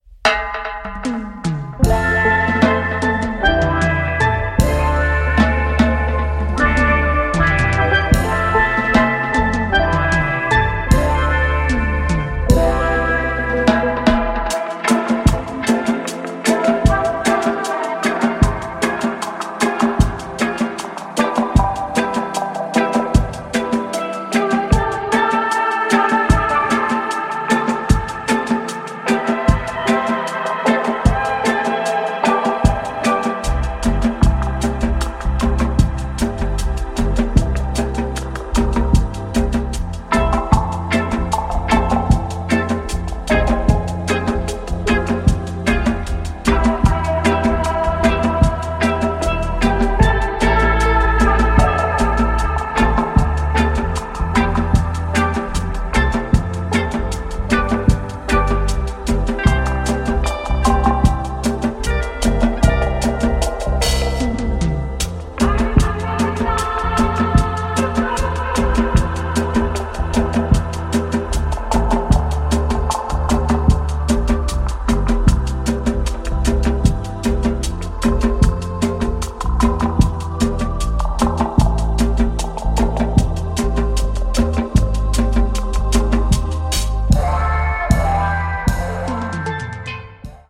Disco / Balearic Reggae / Dub